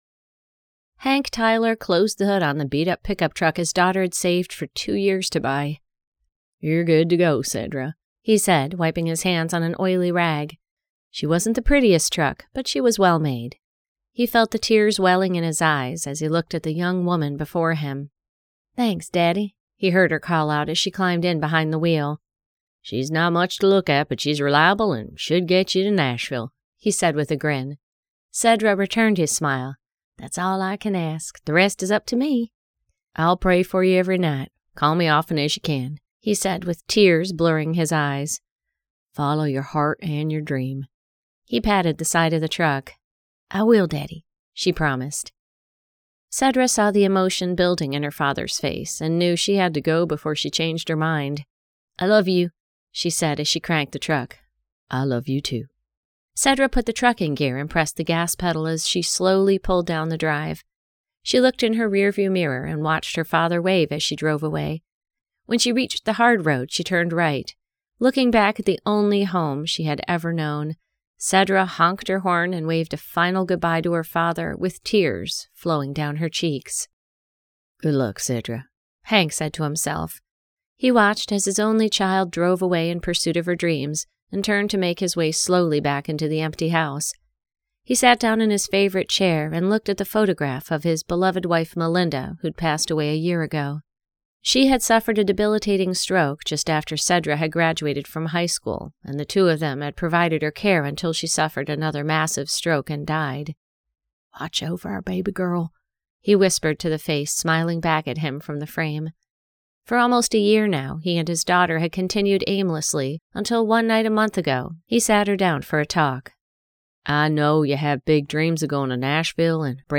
Six Strings and a Dream by Ali Spooner Songwriters Book 1 [Audiobook]